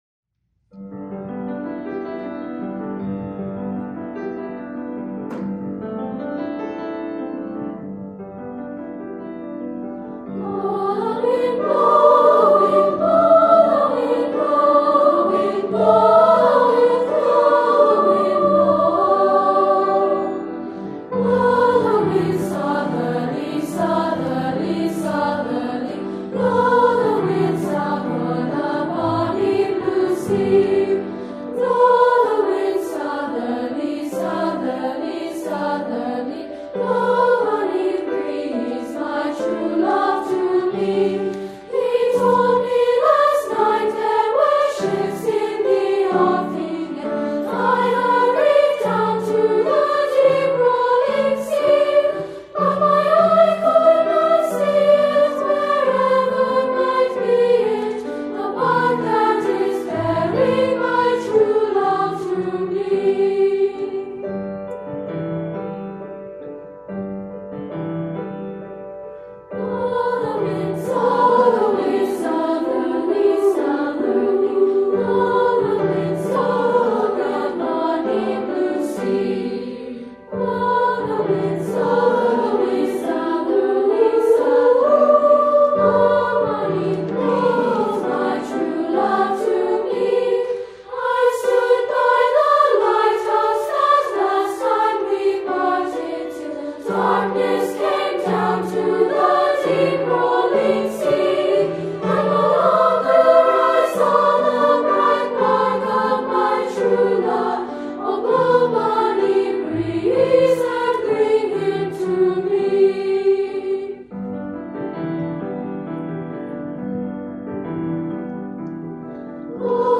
Voicing: 2-Part or SSA